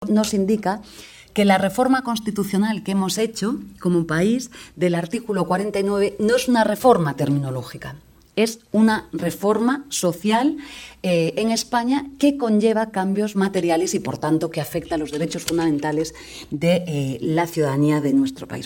Yolanda Díaz enel acto de SERVIMEDIALa vicepresidenta segunda del Gobierno y ministra de Trabajo y Economía Social, Yolanda Díaz, destacó el 6 de marzo que la reforma del despido por incapacidad derivada de una discapacidad sobrevenida, que inicia ya su procedimiento, se produce porque “nuestro país tiene una deuda con las personas con discapacidad que se debe corregir", dijo formato MP3 audio(0,30 MB)Así lo manifestó en un diálogo organizado por Servimedia y la Unión de Profesionales y Trabajadores Autónomos (UPTA) bajo el título ‘Los retos y el futuro del trabajo autónomo’, celebrada en el marco del 35º aniversario de Servimedia.